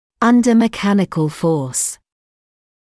[‘ʌndə mɪ’kænɪkl fɔːs][‘андэ ми’кэникл фоːс]под воздействием механической силы